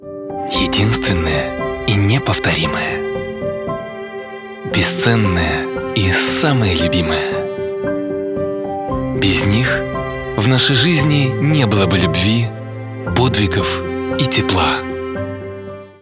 Муж, Другая/Средний
RME Babyface pro, LONG, DBX, Digilab, Neumann TLM 103, 023 Bomblet, ARK 87, Oktava MK-105, sE 2200